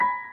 piano75.ogg